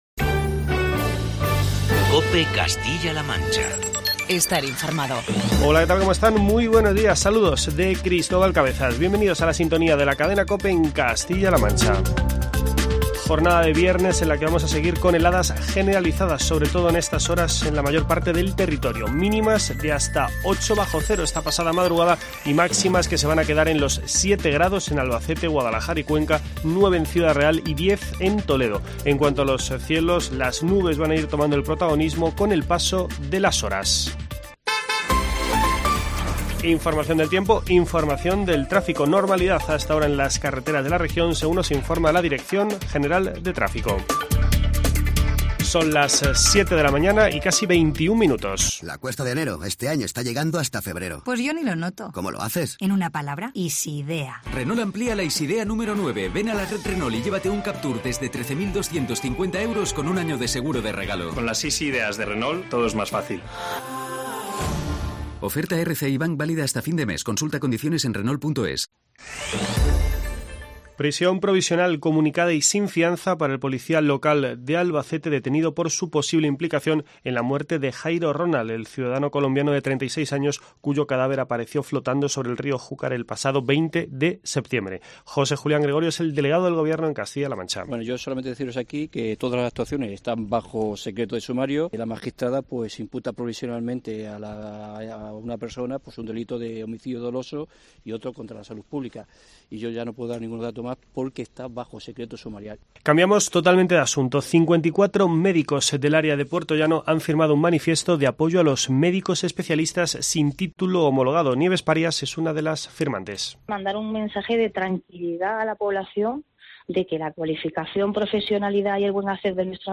AUDIO: Toda la actualidad en los informativos matinales de la Cadena COPE.